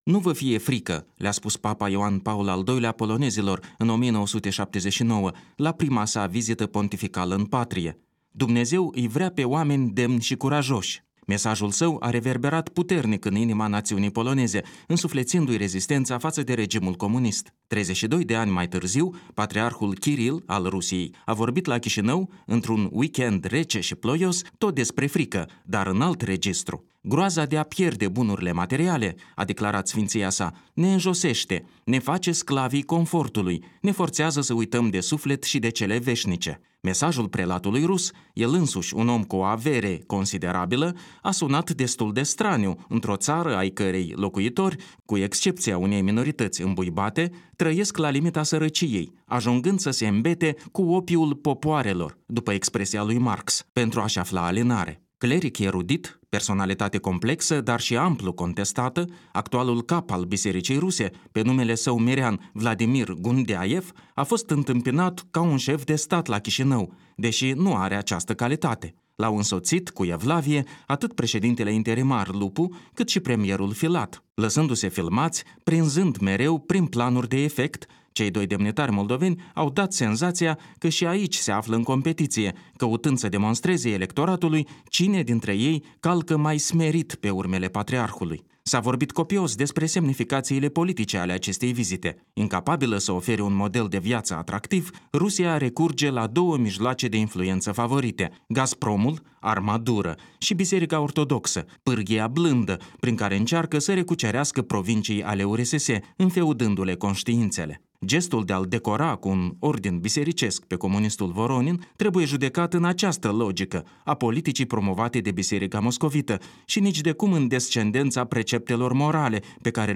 Un punct de vedere